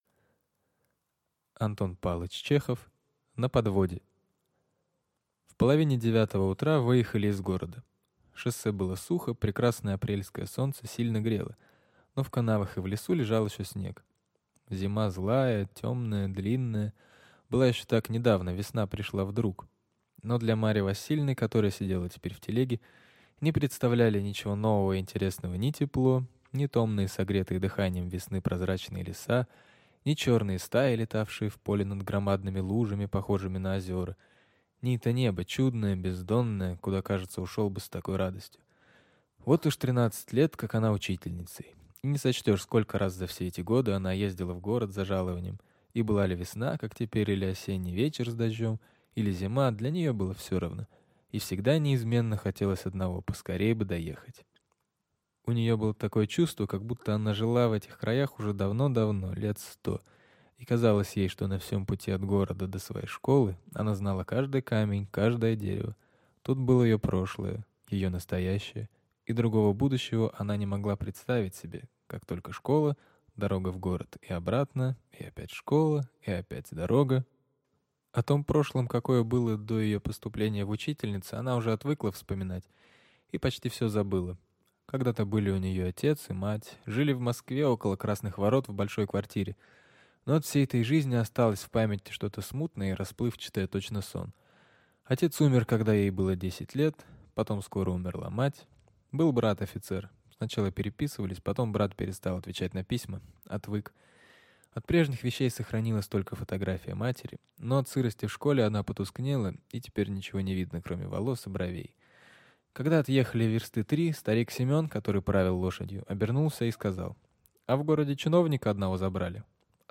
Аудиокнига На подводе